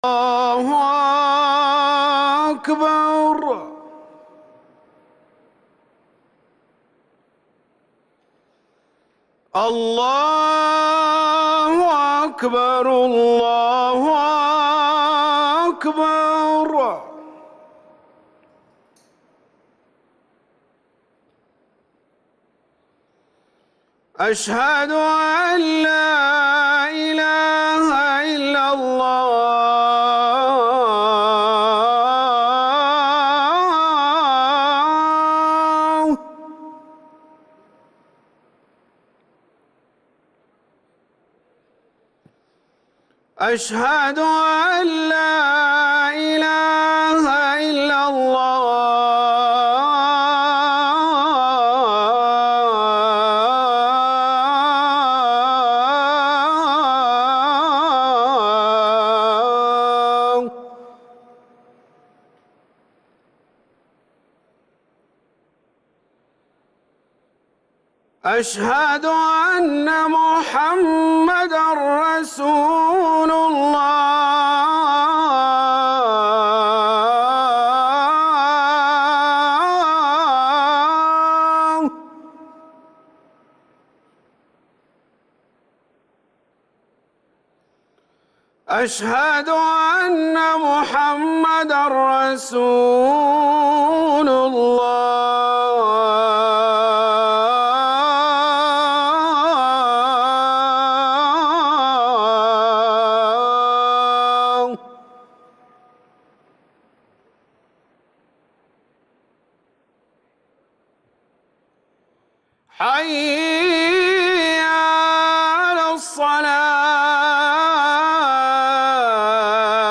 روائع الأذان